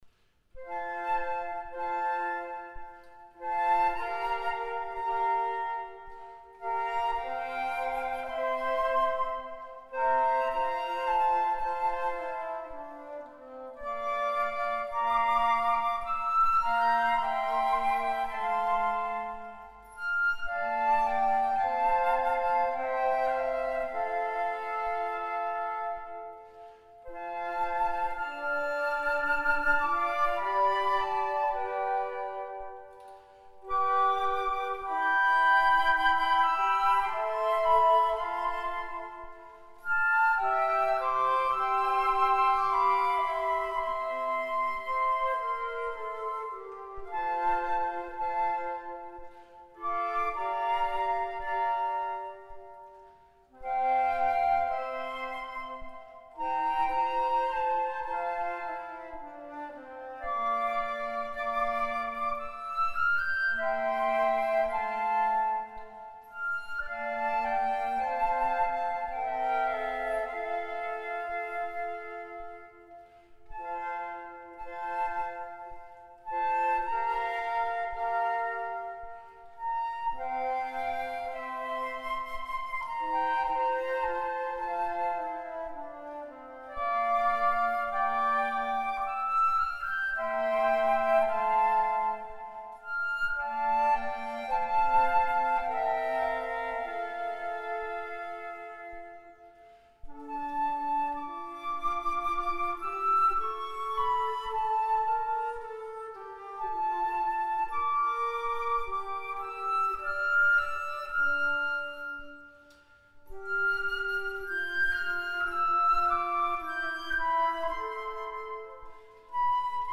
für 4 Flöten